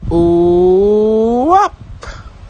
owop Meme Sound Effect
Category: Reactions Soundboard